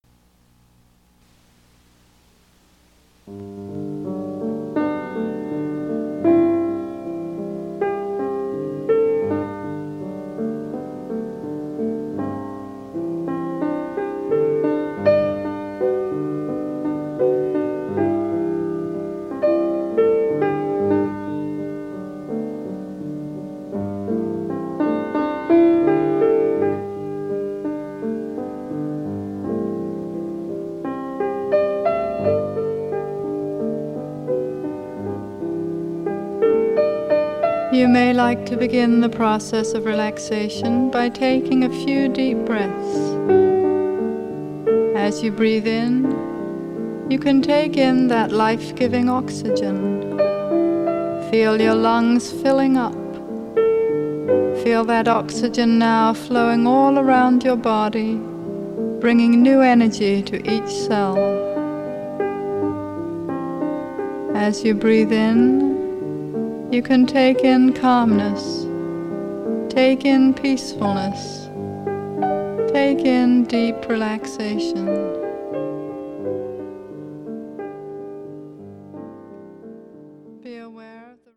Seasons for Healing: Summer (Guided Meditation)